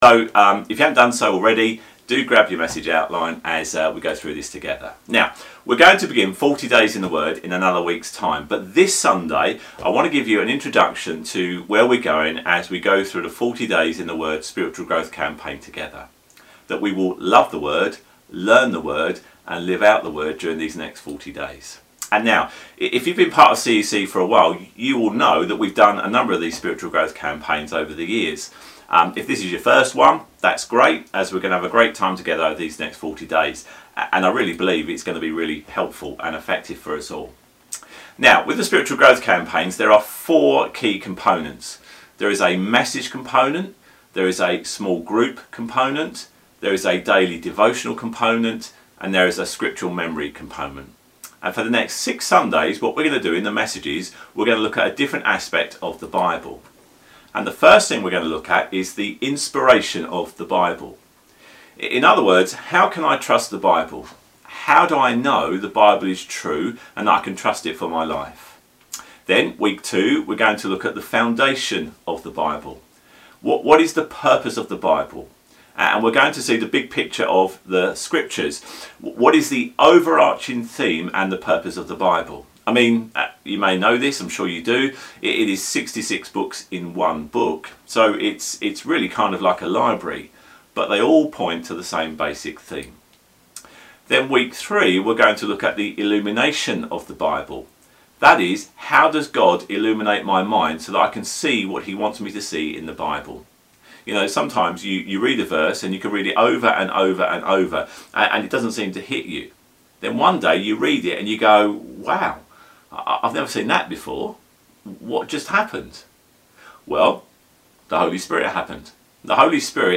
God wants you to learn to encounter the Bible with every one of your senses. In the message on Sunday we’re going to look at this using James 1:19-25 The link to the outline is here .